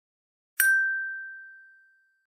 42. ding